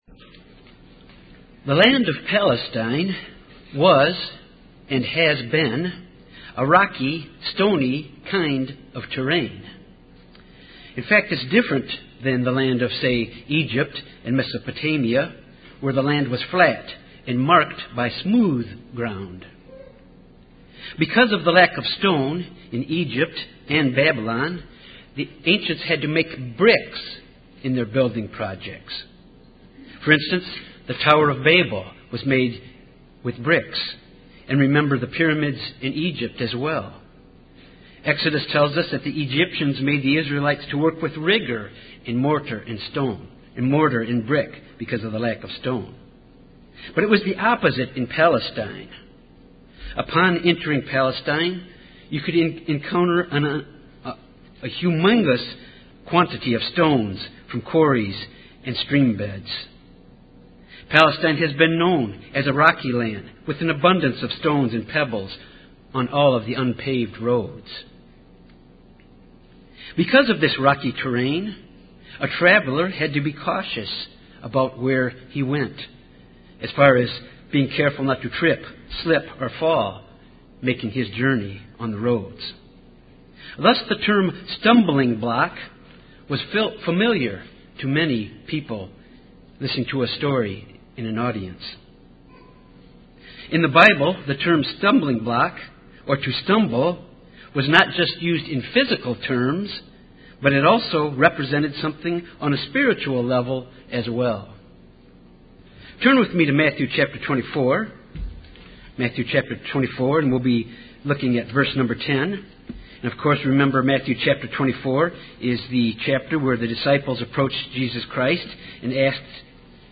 Given in Little Rock, AR Jonesboro, AR